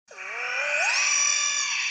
Archivo:Grito de Aurorus.ogg
Categoría:Aurorus Categoría:Gritos de Pokémon de la sexta generación